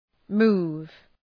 Προφορά
{mu:v}